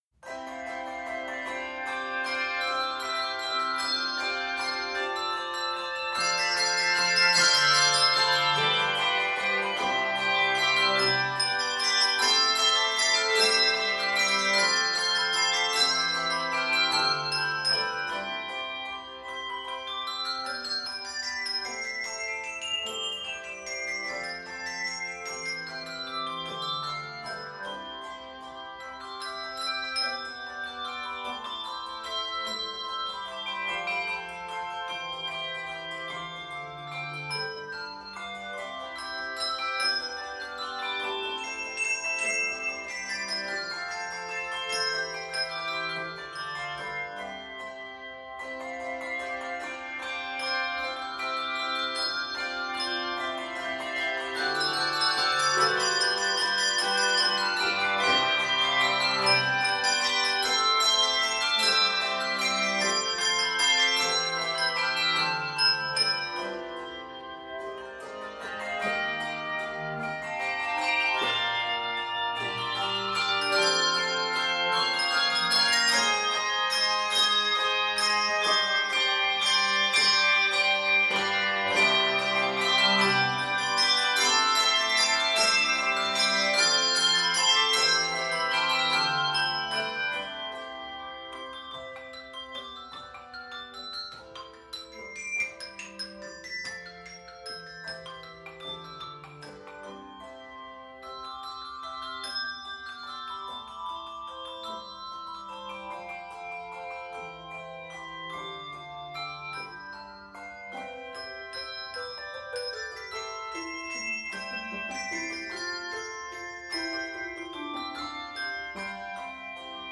Exuberant
Full festive
Interesting use of martellato.